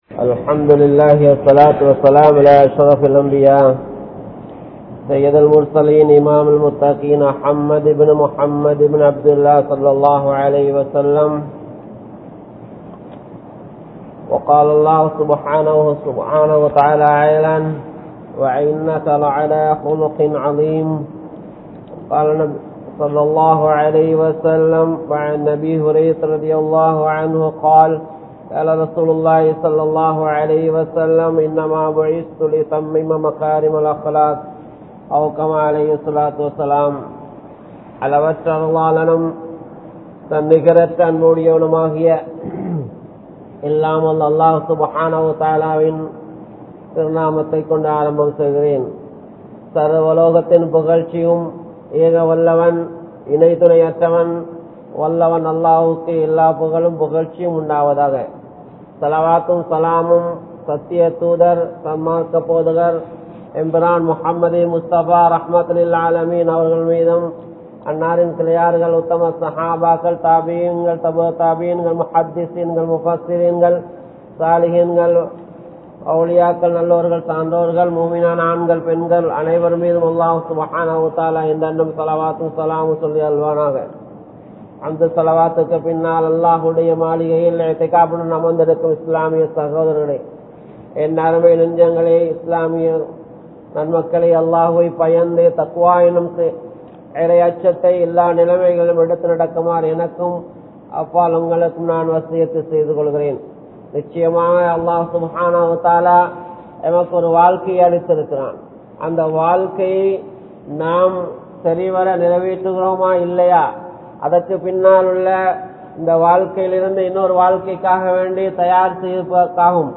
Ahlaaq (அஹ்லாக்) | Audio Bayans | All Ceylon Muslim Youth Community | Addalaichenai